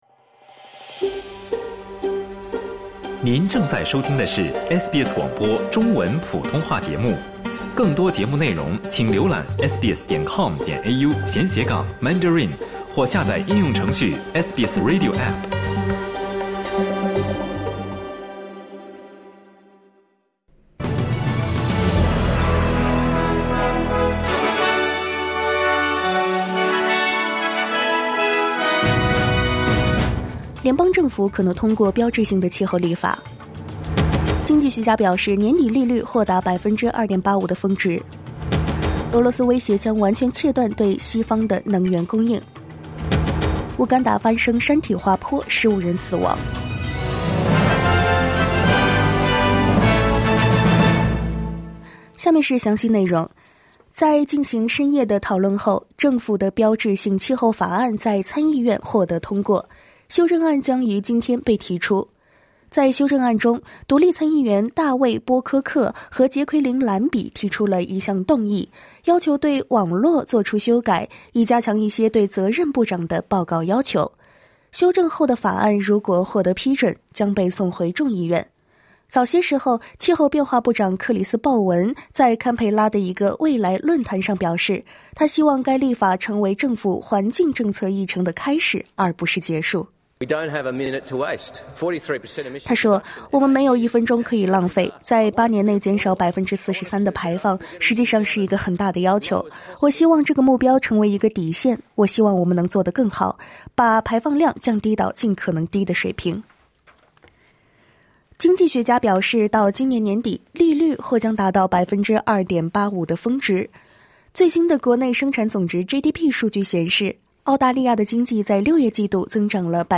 SBS早新闻（9月8日）
请点击收听SBS普通话为您带来的最新新闻内容。